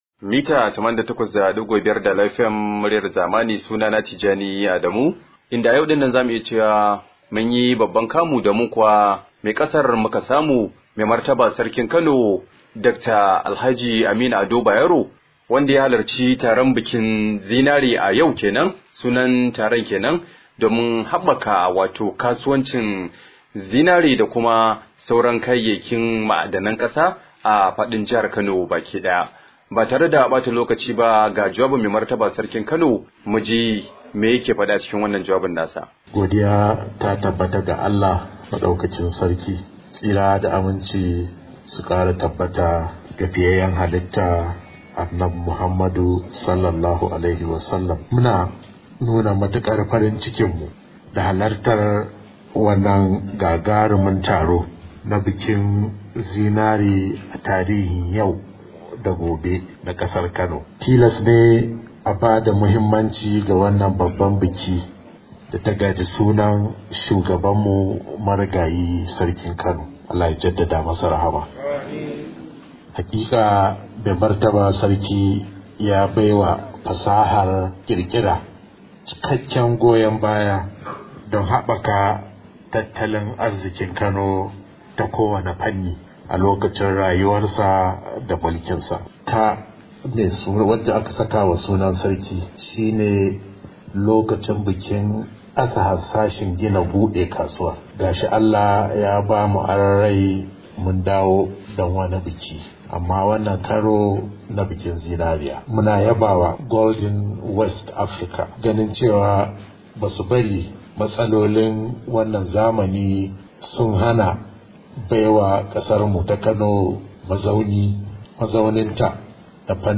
Alhaji Aminu Ado Bayero ya bayyana hakan ne a taron bikin Zinare da ya gudana a ranar Litinin domin habbaka harkar Zinare.